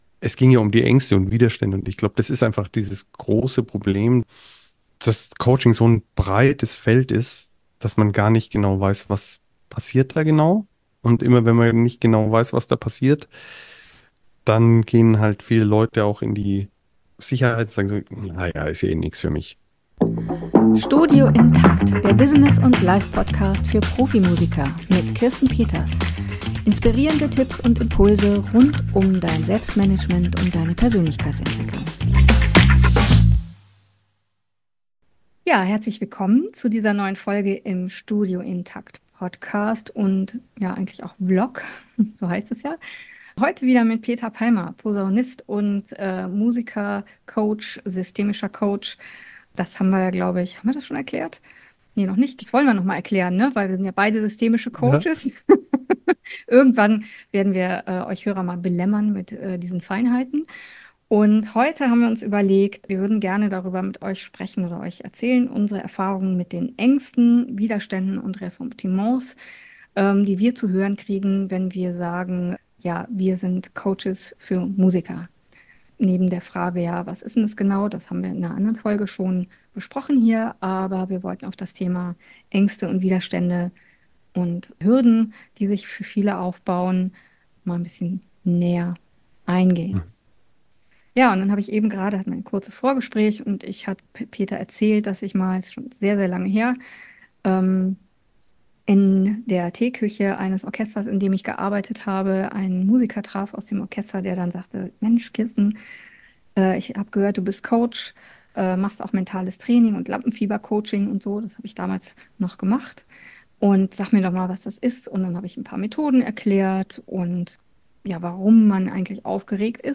Ab und an treffen wir uns am virtuellen Küchentisch und tauschen uns über unsere Erfahrungen in unserer Arbeit mit Profimusiker*innen aus.